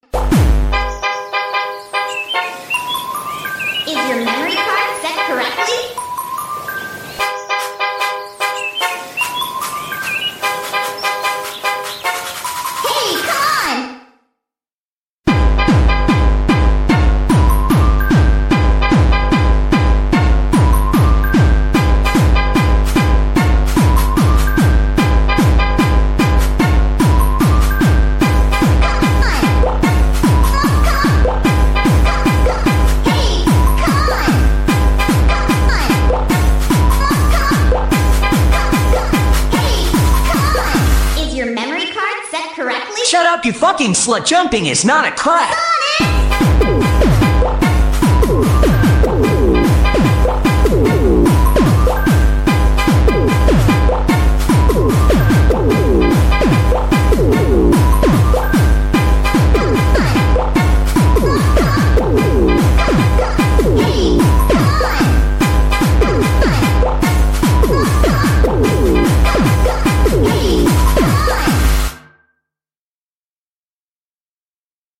2025-01-22 15:17:37 Gênero: Funk Views